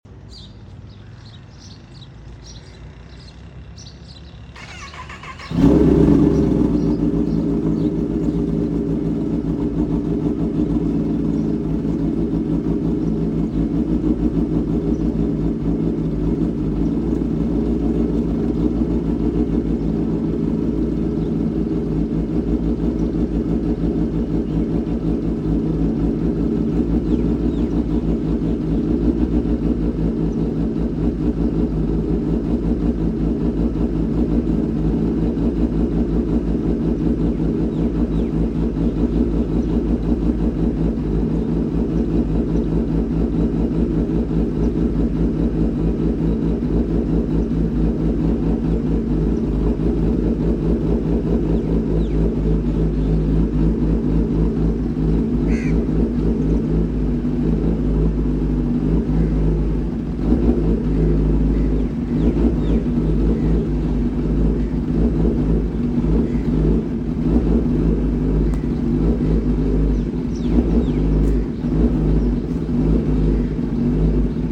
Heavybike Sound! Kawasaki! 🔥 Full Sound Effects Free Download
Heavybike sound! Kawasaki! 🔥 Full sound effects free download